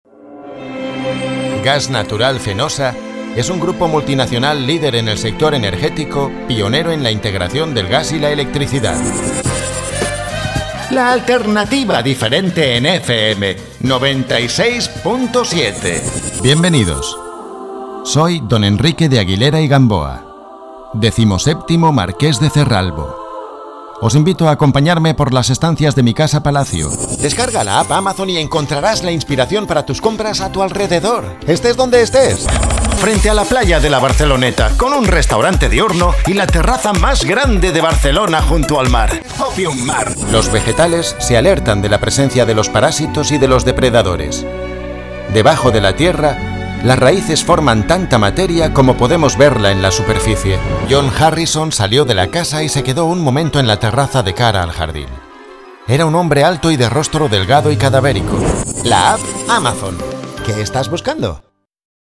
kastilisch
Sprechprobe: Sonstiges (Muttersprache):
I have own recording studio PRO TOOLS LE 8 and availability of travel to other studies in the area.